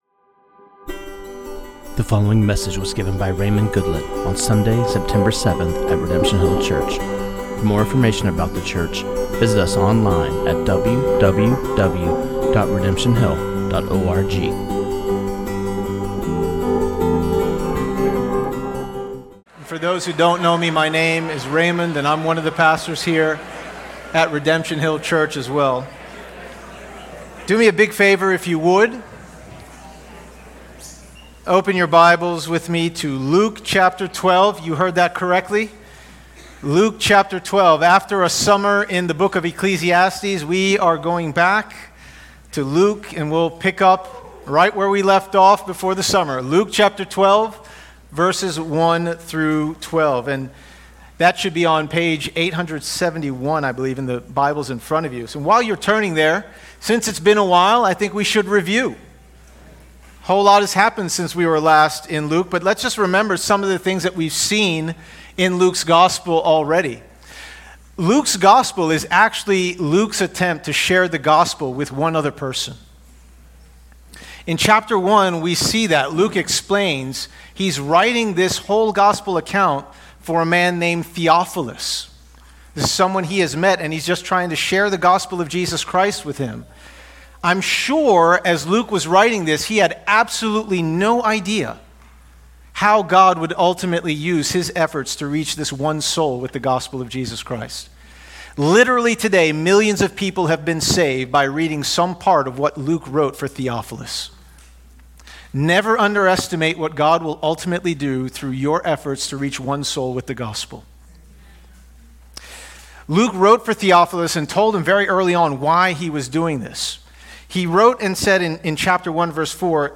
This sermon on Luke 12:1-12 was preached